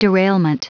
Prononciation du mot derailment en anglais (fichier audio)
Prononciation du mot : derailment